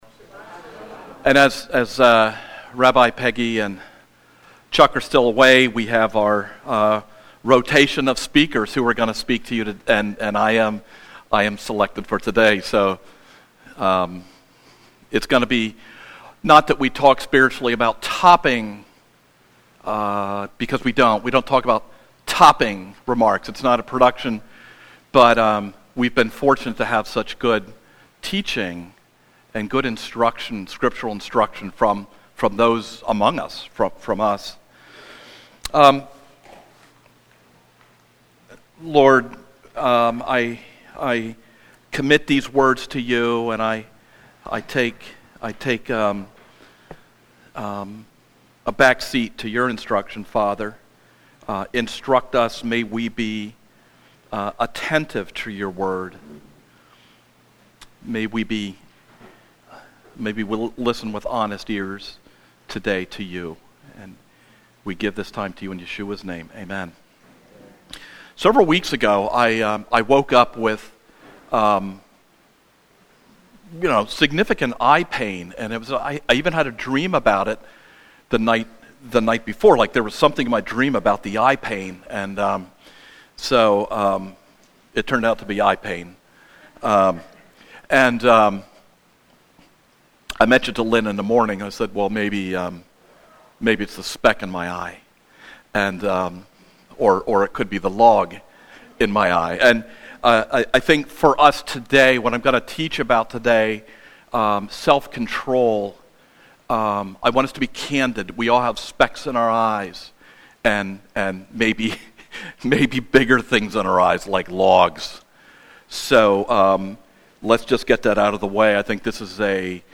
Podcast of the Weekly Sermons of the Congregation Lion of Judah